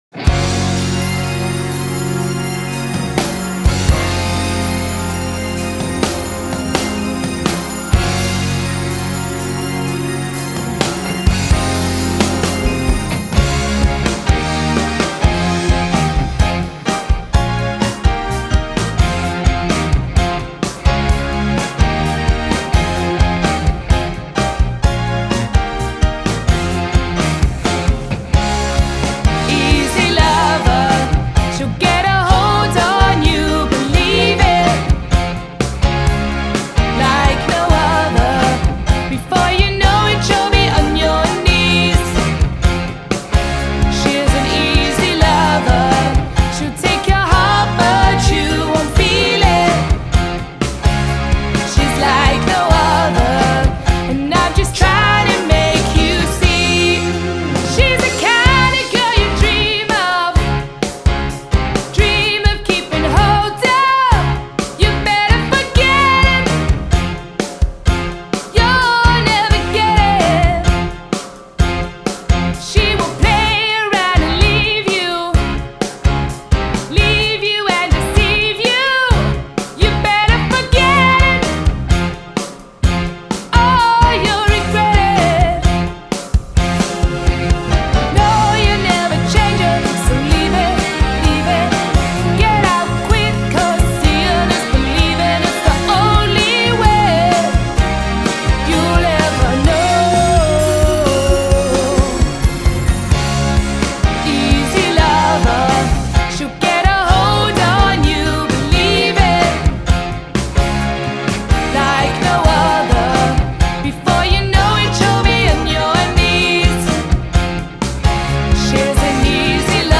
Superb Live Band with a Rocky Edge